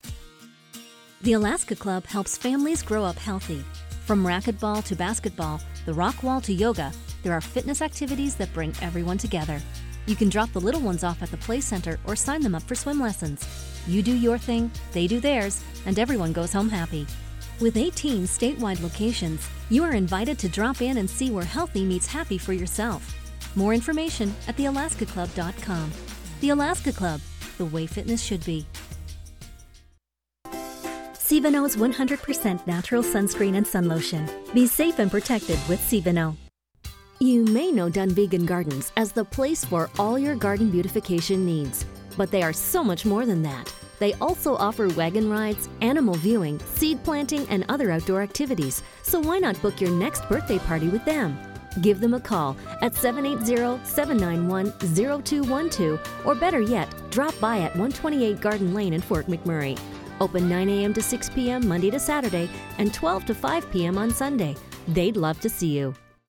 Anglais (canadien)
Expérimenté
Précis